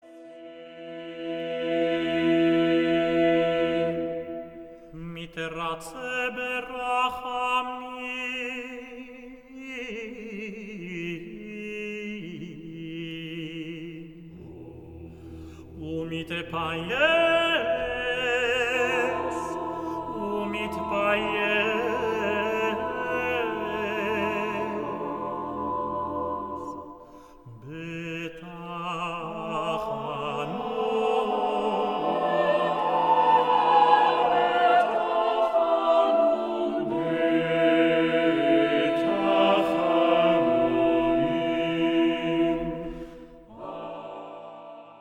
Cantorial and Choral masterpieces
recorded in Berlin